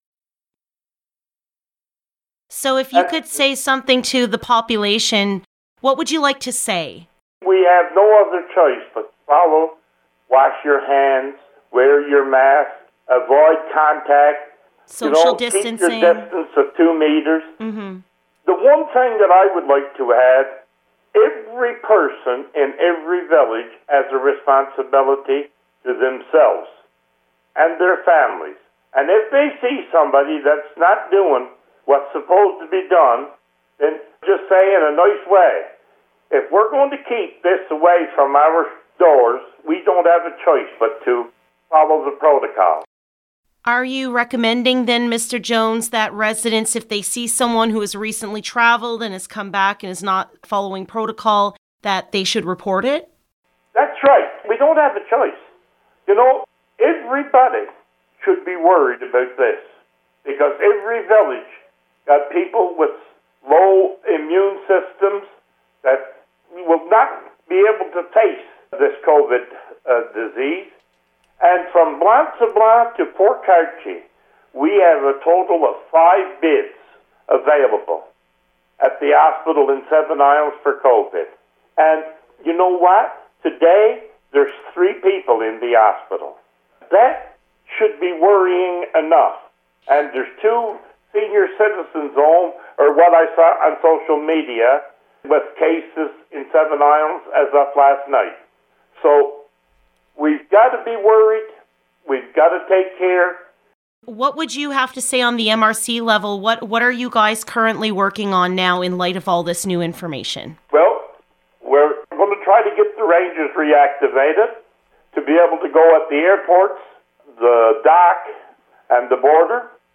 Listen to the interview with Warden Randy Jones by clicking on the sound files below (click on the speaker icon next to the sound file and a media player will open up to hear the interview).